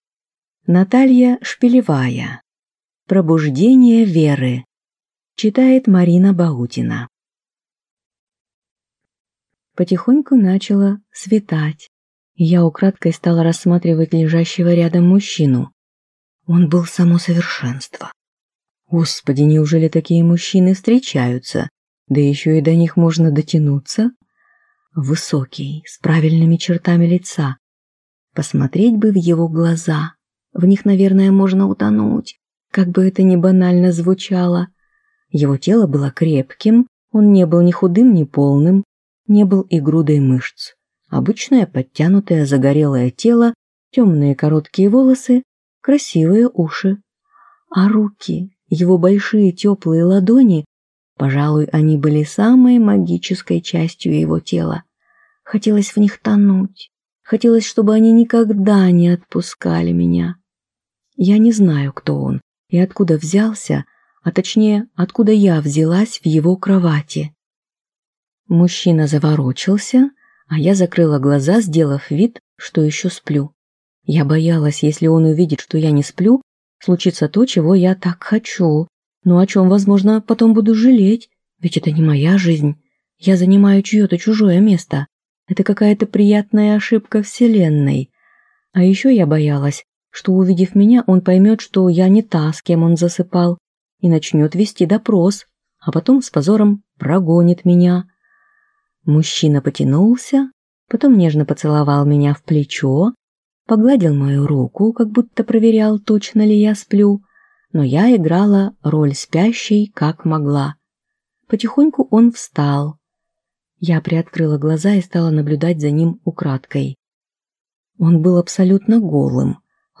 Аудиокнига Пробуждение Веры | Библиотека аудиокниг